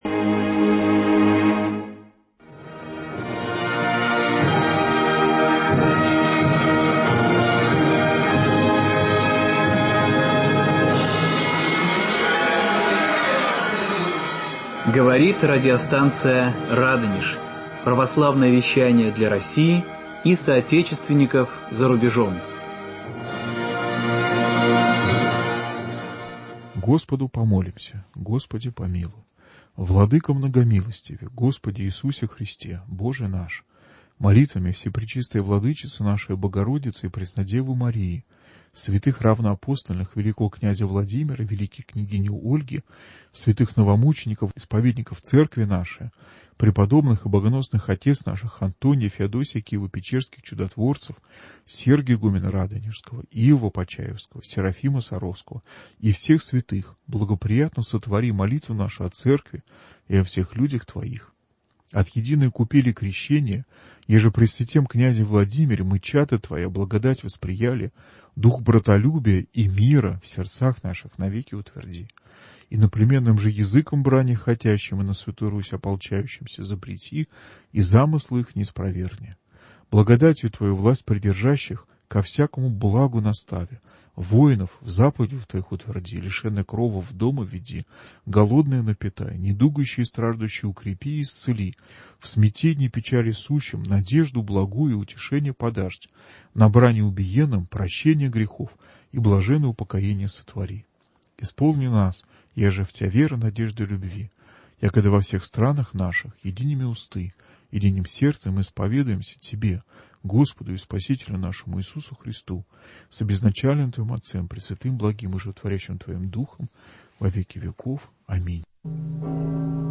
Фрагменты всенощного бдения, совершённого в Сретенском мужском монастыре г. Москвы под праздник Благовещения Пресвятой Богородицы.